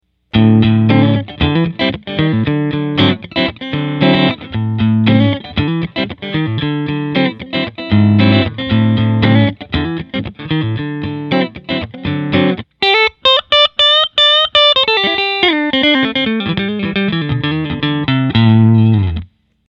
I used my loop station to record a 20 second guitar riff used it to record 5 different amp settings before I modded the amp.
For test four, I cranked the mids all the way.
Although the “after” clearly sounds better because of the bass, the mid-range has that same nasal sound in both examples.